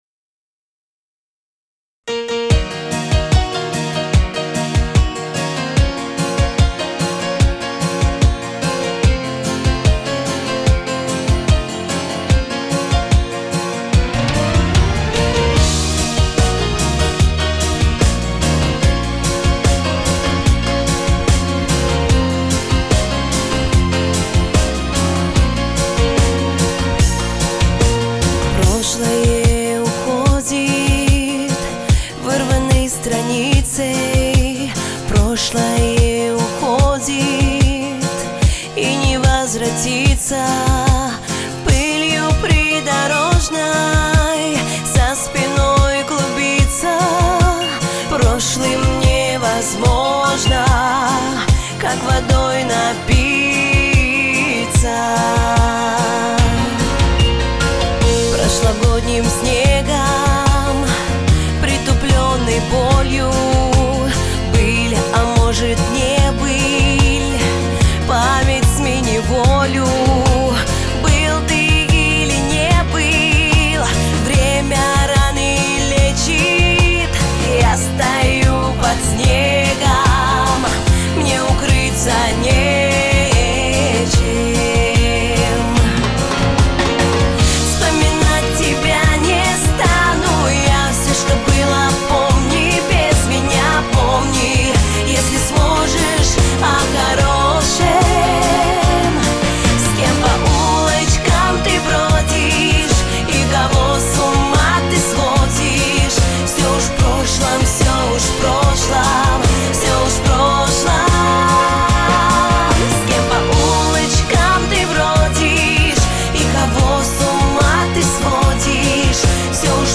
Жанр: Шансон